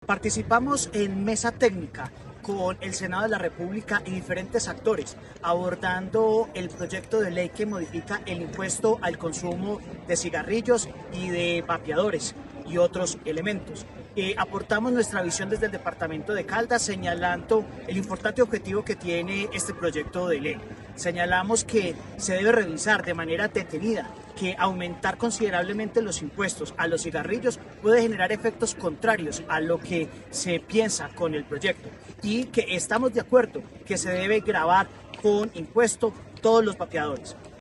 John Alexander Alzate Quiceno, secretario de Hacienda de Caldas